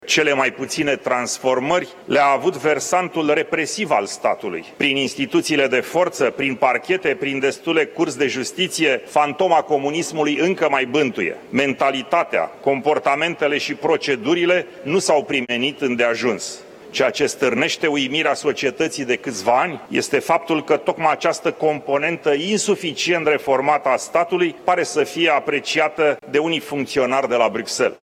După discursul liderului european, la tribuna Parlamentului a venit președintele Senatului, Călin Popescu Tăriceanu.